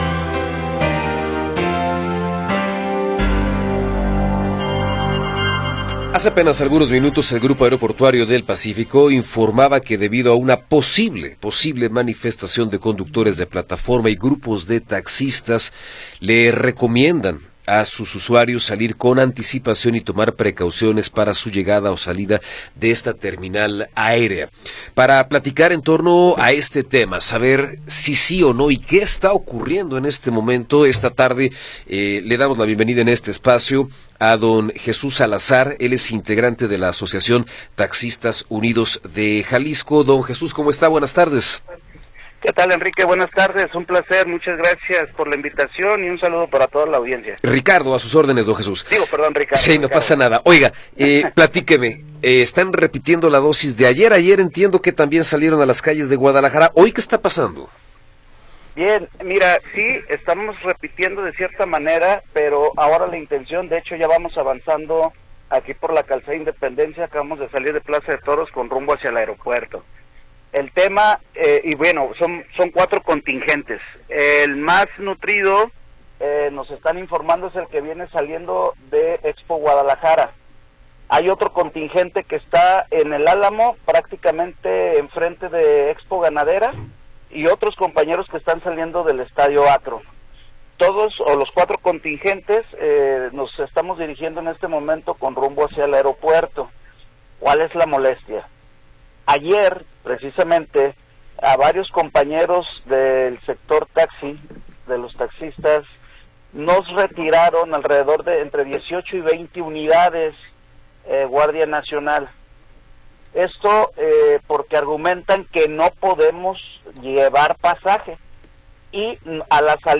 ENTREVISTA2.m4a